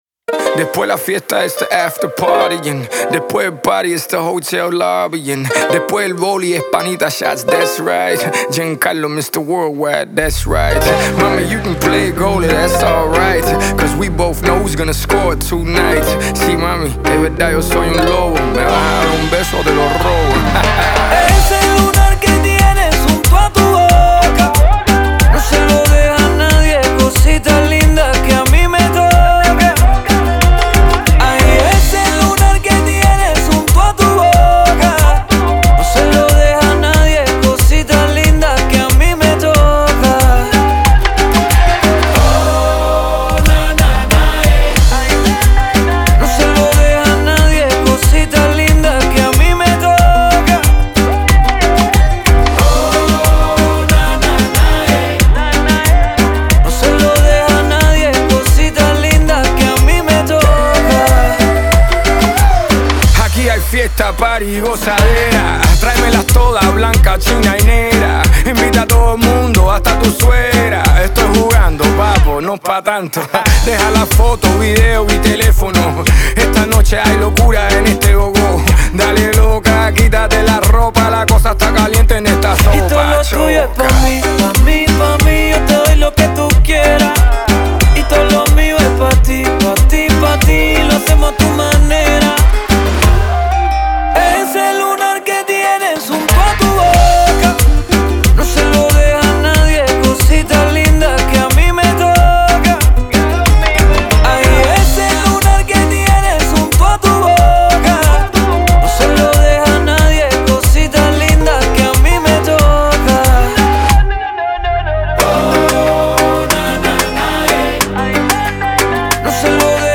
это яркая и зажигательная песня в жанре латинского попа